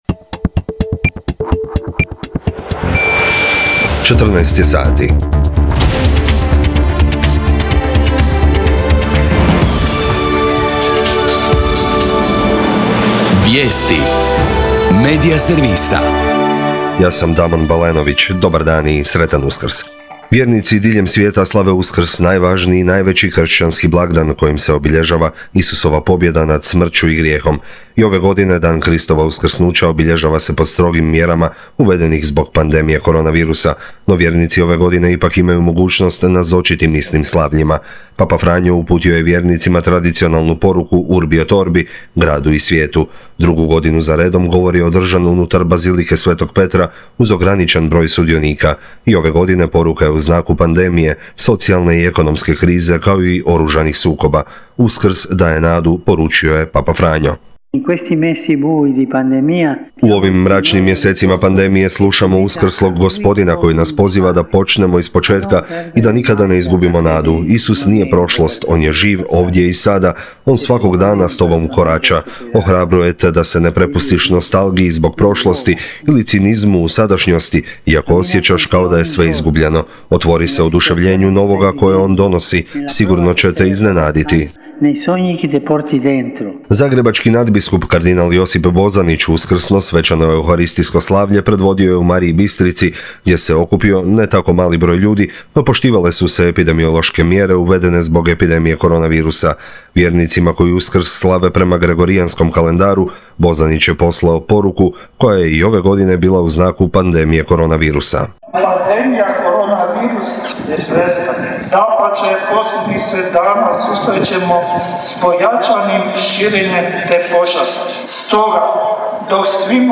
VIJESTI U 14